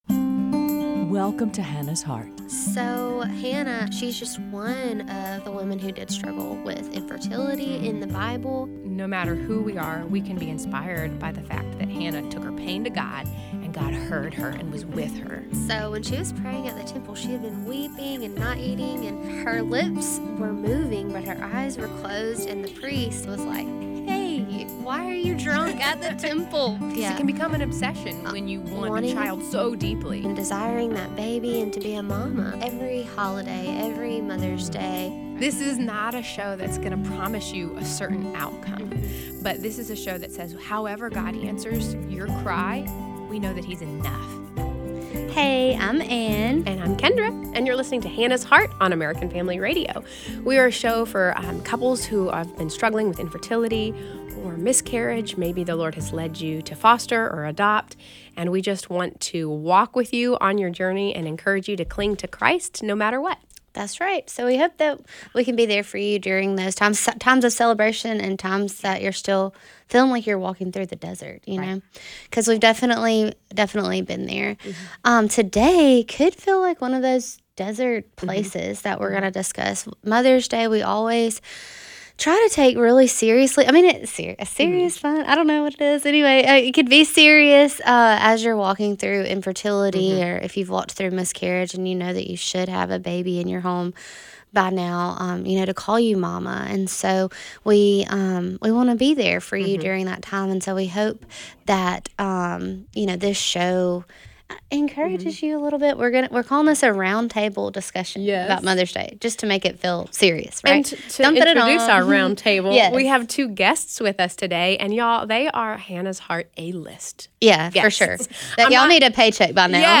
Mother's Day Round Table